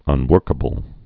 (ŭn-wûrkə-bəl)